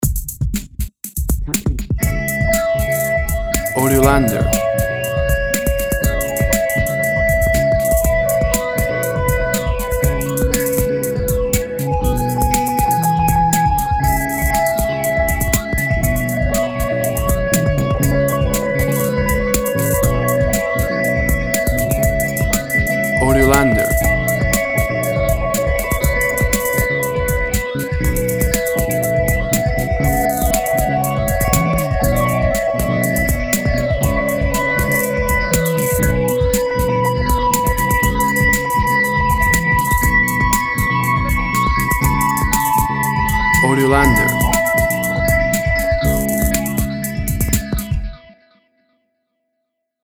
Music instrumental, Downbeat.
Tempo (BPM) 75